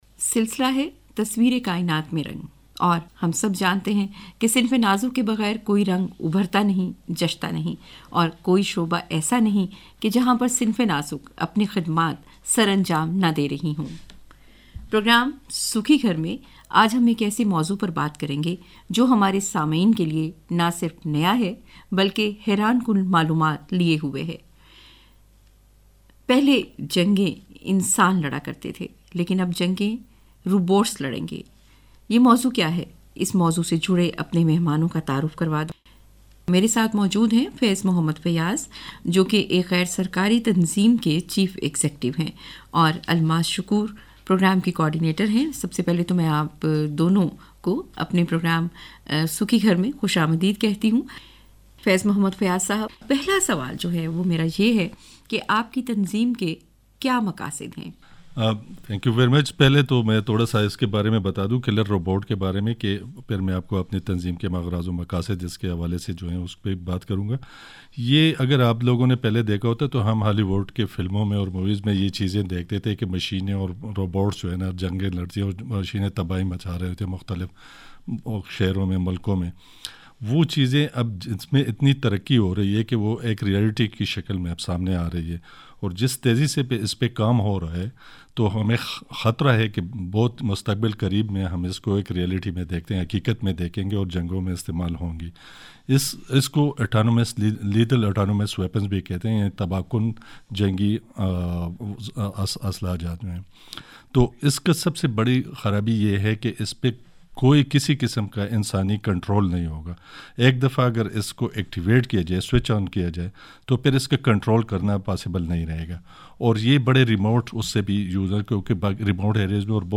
CMDO radio program on Killer Robots CMDO Sent Letters to Different Embassies to submit comprehensive national working papers/commentaries to CCW GEE CMDO officials met with the Higher Education Commission Islamabad to work on Killer Robots policy CMDO arranged advocacy webinar on Killer Robots with IT students